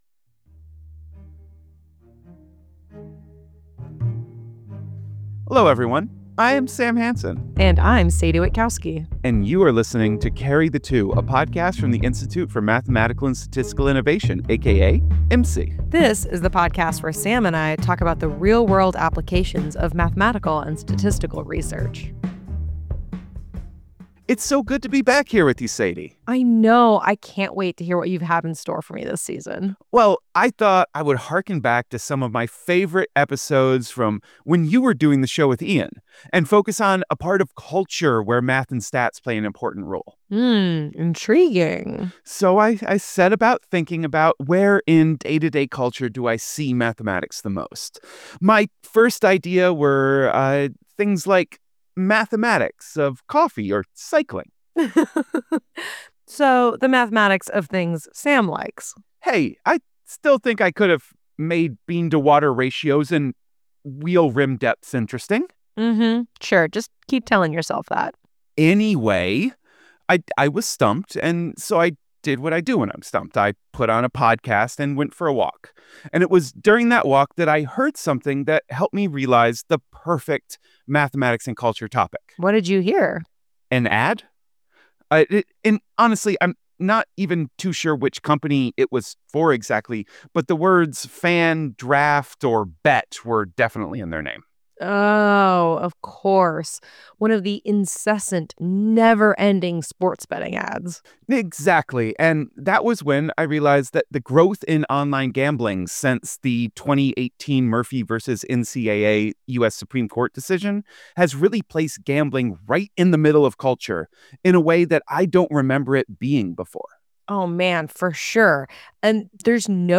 Welcome to Carry the Two, the podcast about how math and statistics impact the world around us from the Institute for Mathematical and Statistical Innovation. In this season of Carry the Two we are going to be examining how math and stats intersect with the world of gambling. This episode is all about Lotteries.